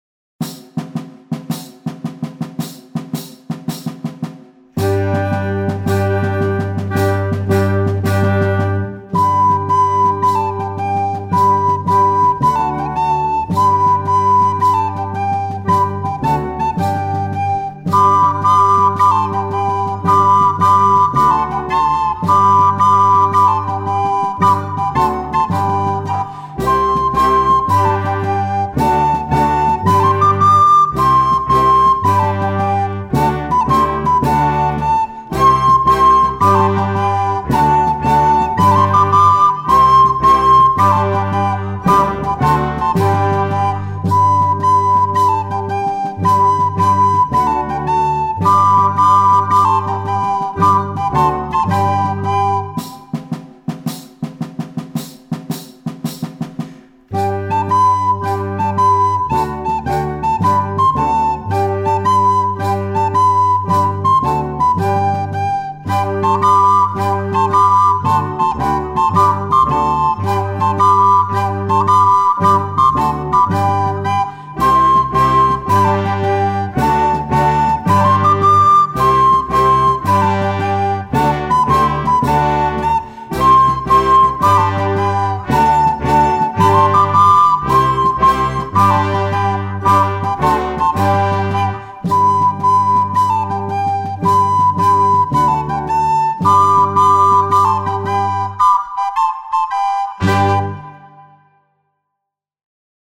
MEDIEVAL RONDEAU (blokfluit) - Himalaya music